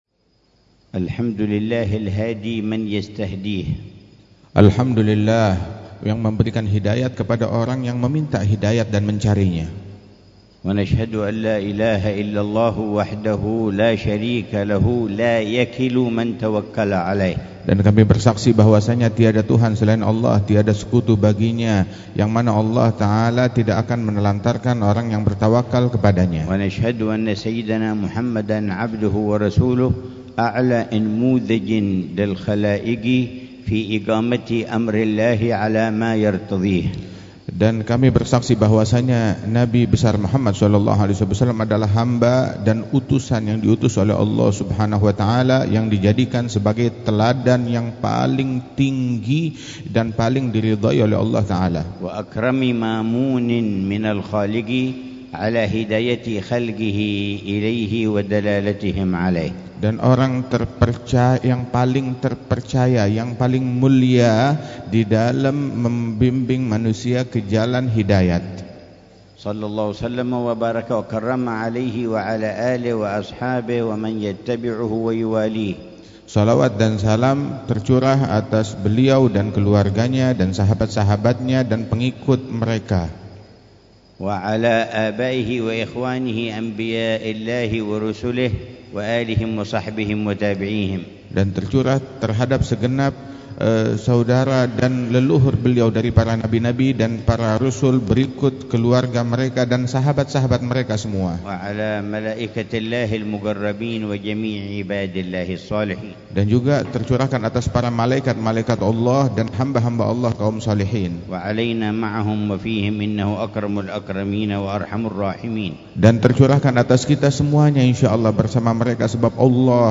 ملتقى العلماء والجمعيات الإسلامية، في جاكرتا، إندونيسيا، الإثنين 28 ربيع الثاني 1447هـ بعنوان: